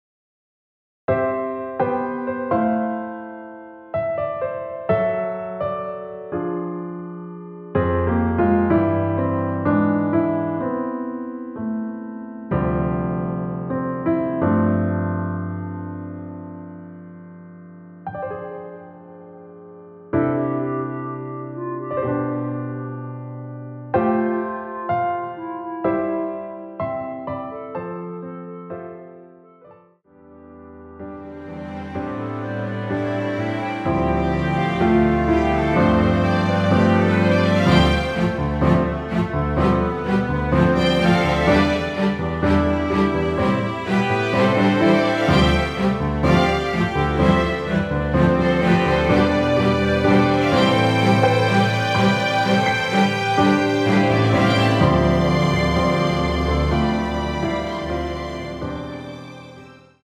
원키에서(-1)내린 멜로디 포함된 MR입니다.(미리듣기 확인)
멜로디 MR이라고 합니다.
앞부분30초, 뒷부분30초씩 편집해서 올려 드리고 있습니다.